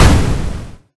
meteorimpact.ogg